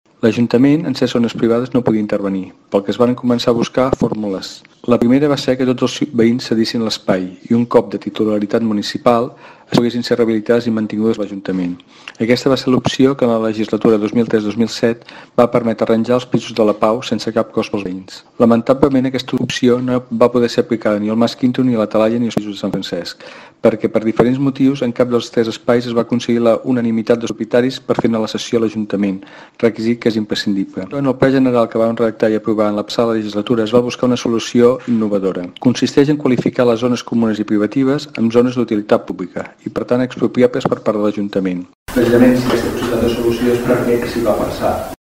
L’advocat i exalcalde Òscar Aparicio reivindica la feina que es va fer durant el seu mandat per pal·liar la situació
Convent_Oscar-Aparicio.mp3